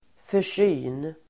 Ladda ner uttalet
Uttal: [för_s'y:n]